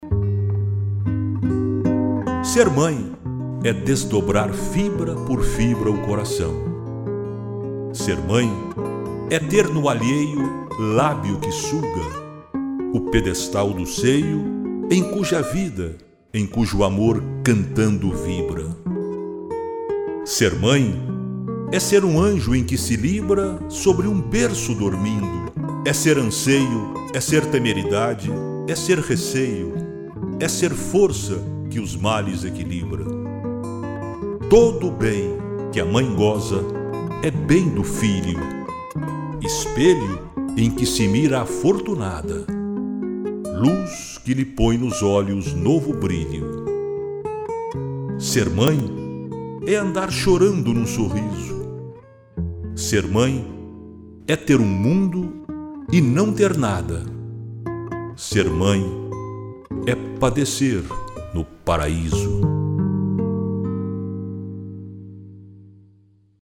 declamação